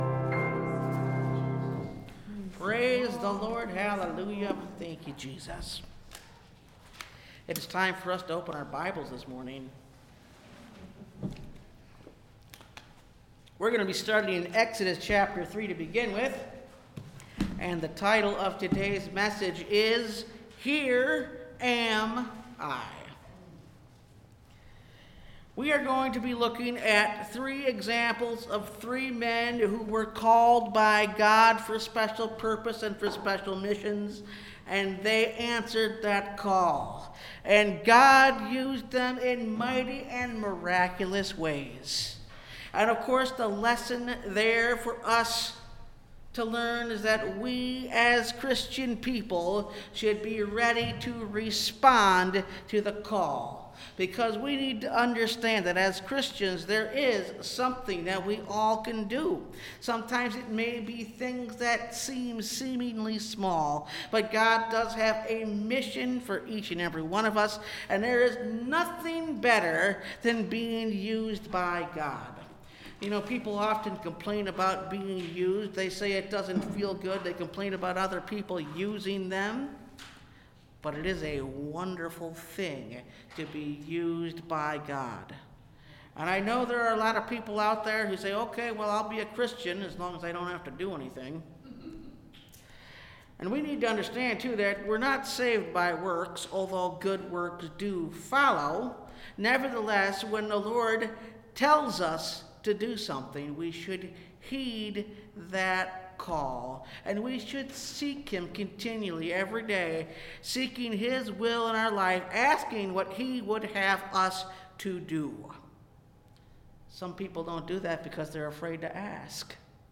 Here Am I (Message Audio) – Last Trumpet Ministries – Truth Tabernacle – Sermon Library